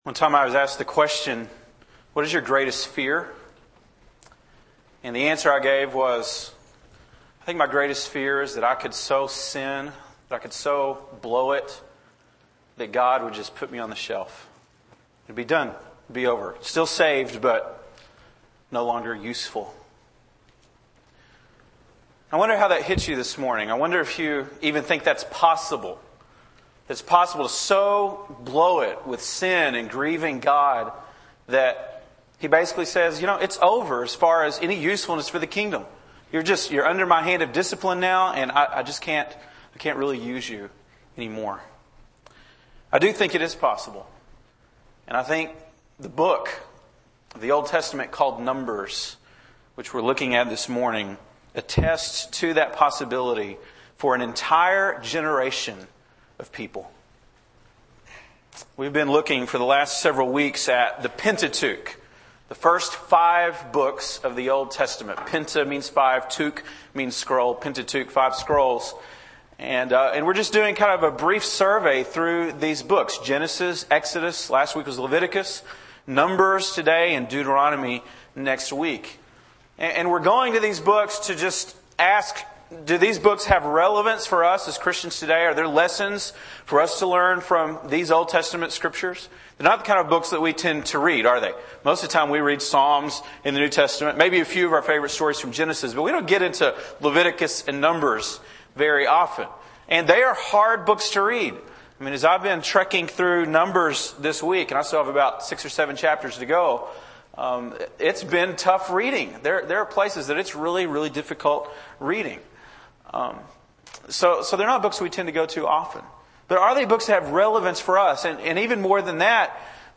August 6, 2006 (Sunday Morning)